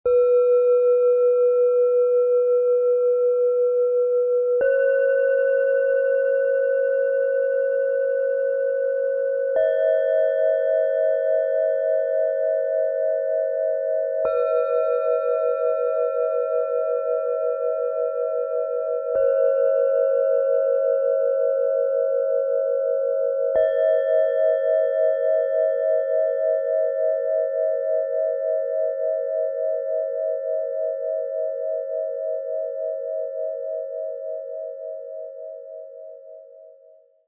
Stille Klarheit: Finde Akzeptanz, Intuition und authentische Kraft - Set aus 3 Planetenschalen, Ø 10,6 -11,5 cm, 1,03 kg
Im Sound-Player - Jetzt reinhören kannst du den Original-Klang genau dieser Schalen live erleben – spüre, wie sie Räume der Stille und inneren Klarheit öffnen.
Tiefster Ton: Lilith
Bengalen-Schale, Schwarz-Gold
Mittlerer Ton: OM
Höchster Ton: Alphawellen
MaterialBronze